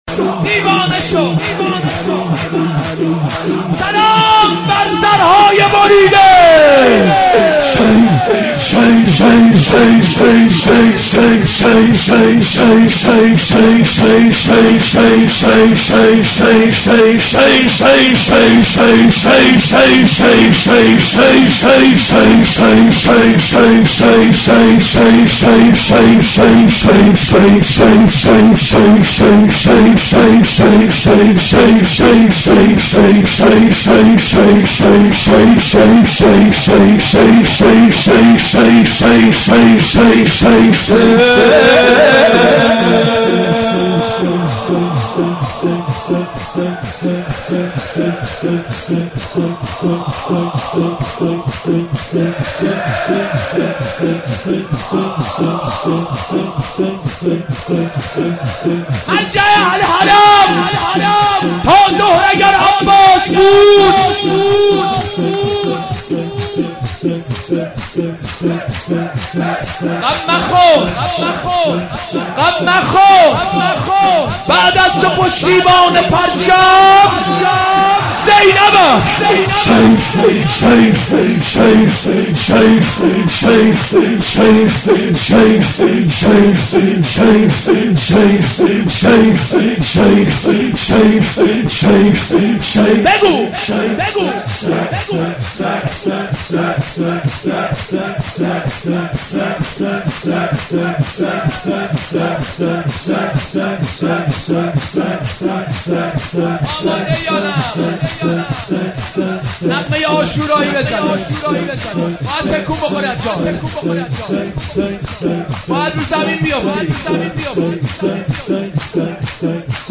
شب دوم محرم
ذکر